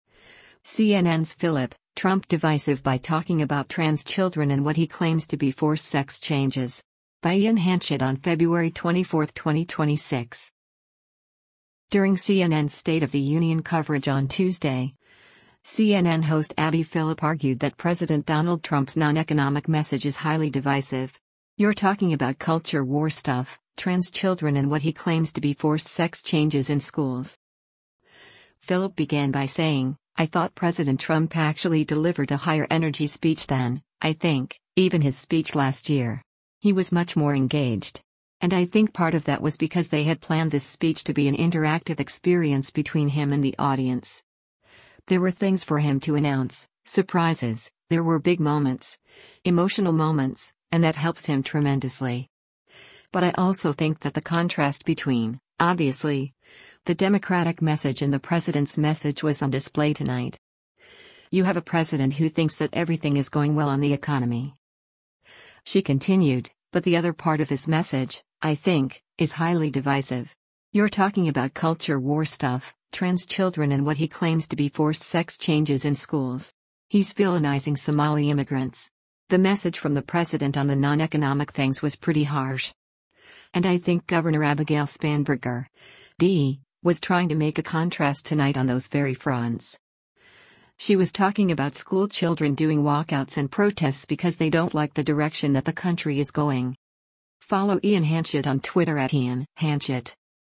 During CNN’s State of the Union coverage on Tuesday, CNN host Abby Phillip argued that President Donald Trump’s non-economic message “is highly divisive. You’re talking about culture war stuff, trans children and what he claims to be forced sex changes in schools.”